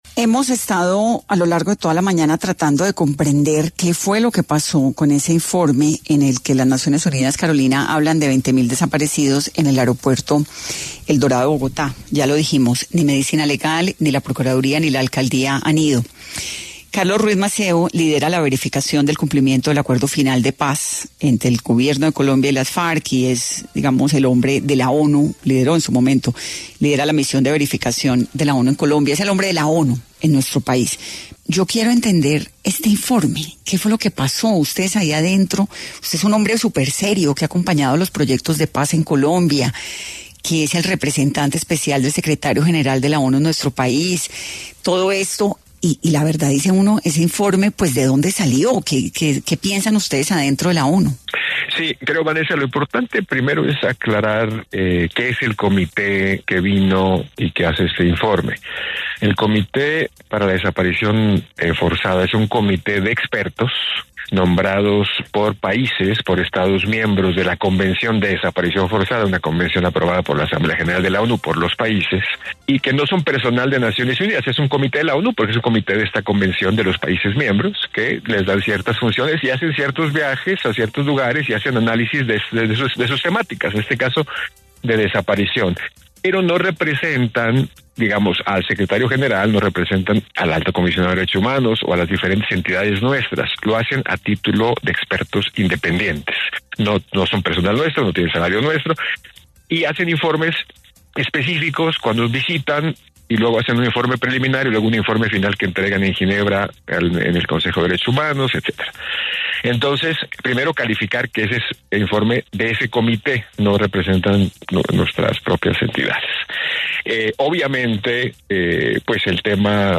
Carlos Ruiz Massieu, representante especial del secretario general de la ONU en Colombia, habló en Caracol Radio sobre la polémica de un informe de la ONU que asegura que existe un hangar en el aeropuerto el Dorado en Bogotá que guarda 20.000 cuerpos sin identificar